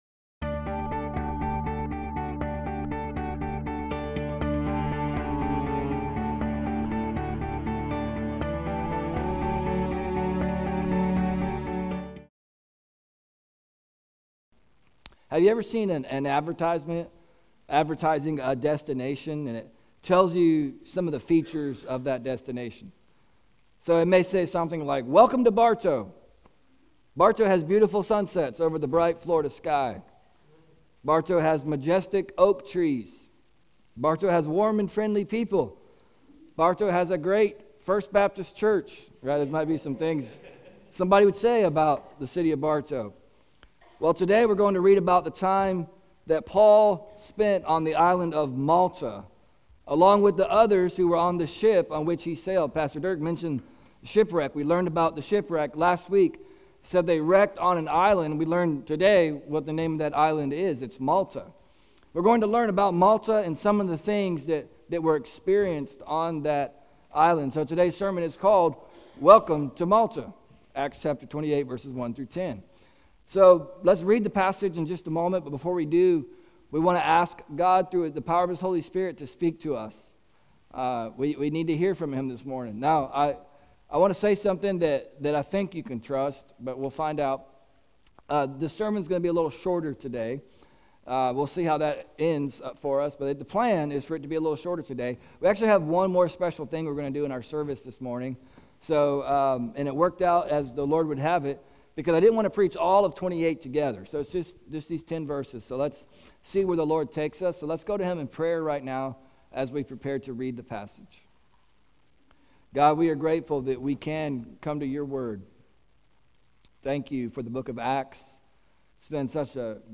Today’s sermon is called “Welcome to Malta!”